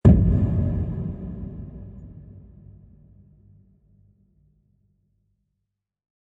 boom.wav